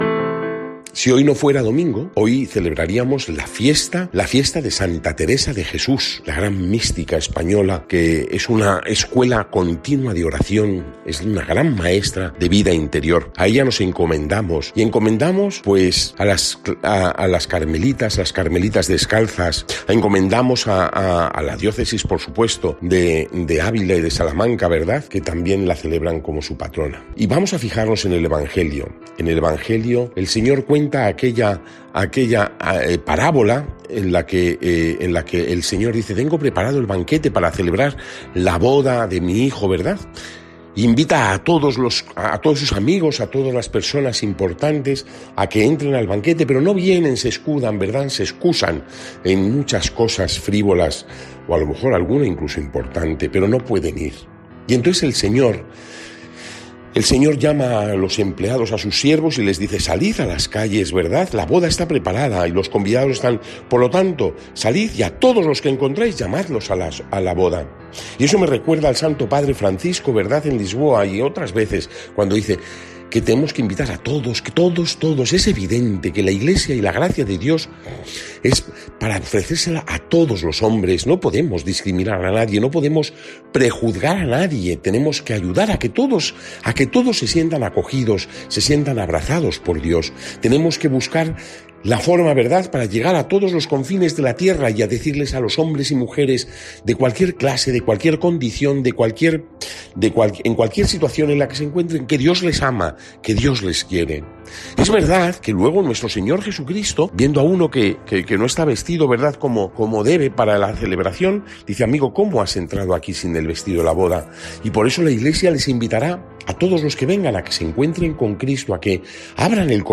Lectura del santo evangelio según san Mateo 22, 1-14